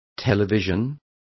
Also find out how television is pronounced correctly.